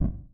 Add some more sound effects
popwall.ogg